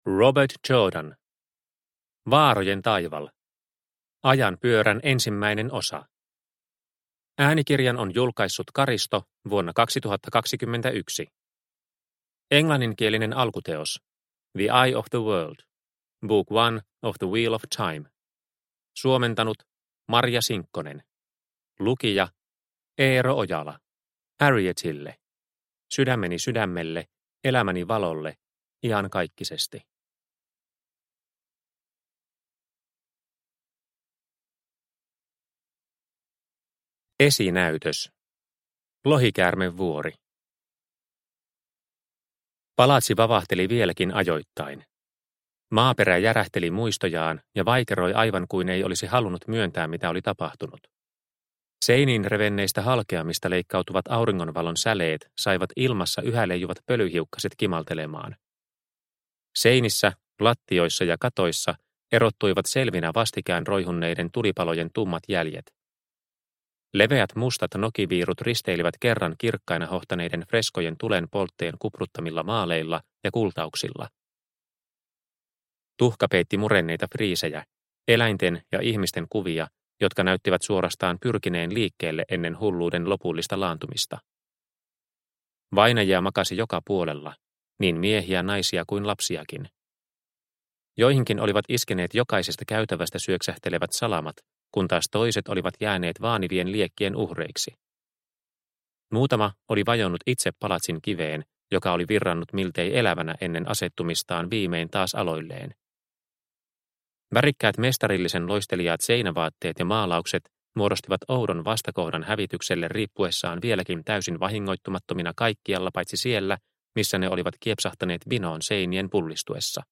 Vaarojen taival – Ljudbok – Laddas ner